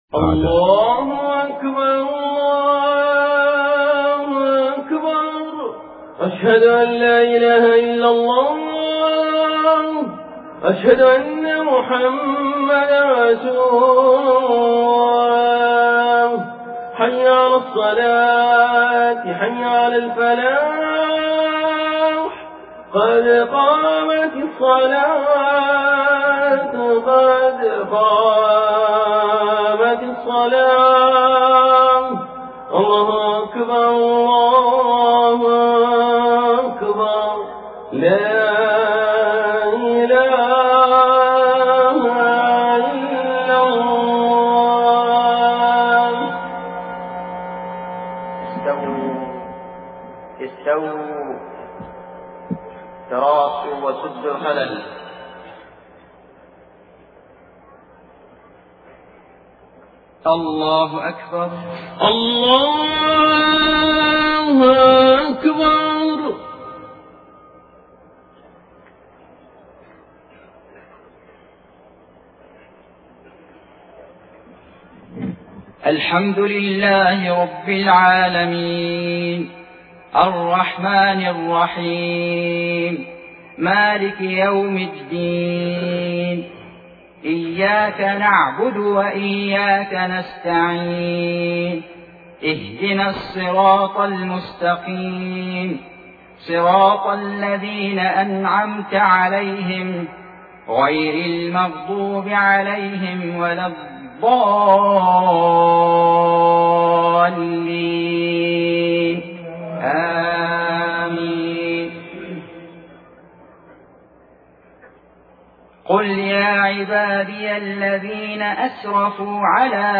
صلاة المغرب 4 محرم 1429هـ من سورة الزمر 53-63 > 1429 🕋 > الفروض - تلاوات الحرمين